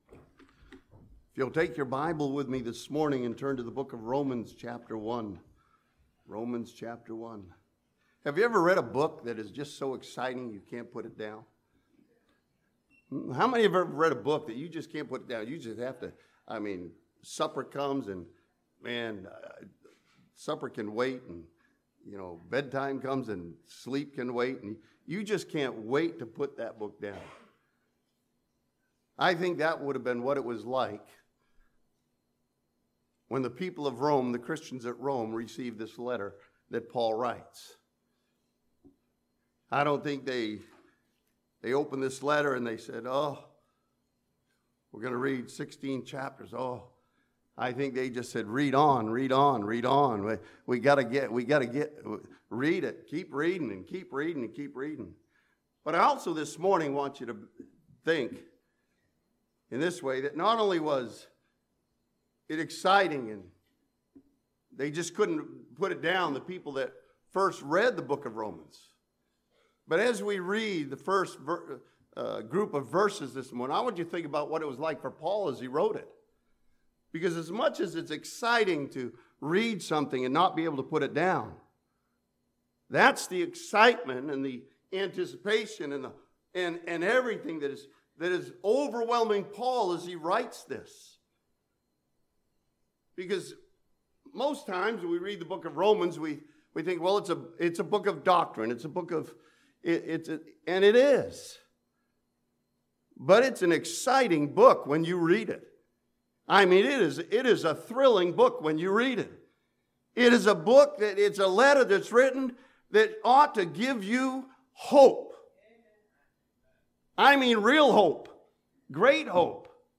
This sermon from Romans chapter 1 challenges believers with the truth that the just shall live by faith.